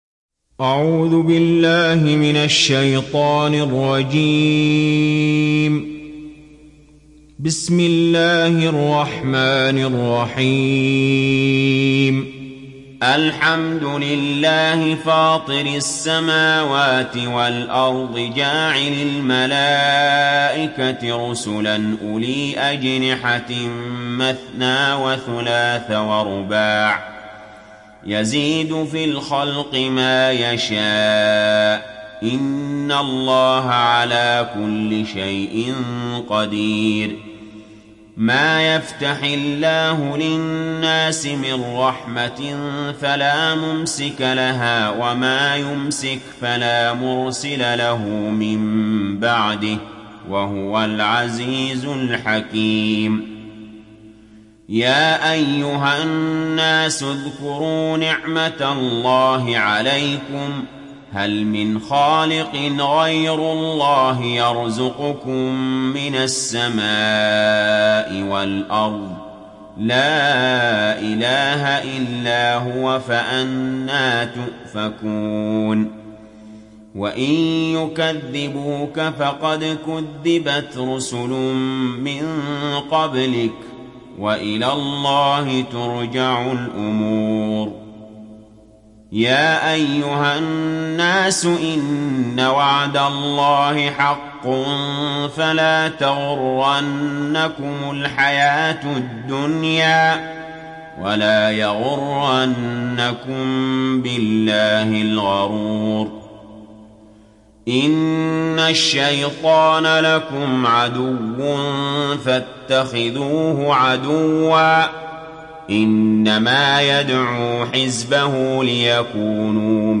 Fatır Suresi İndir mp3 Ali Jaber Riwayat Hafs an Asim, Kurani indirin ve mp3 tam doğrudan bağlantılar dinle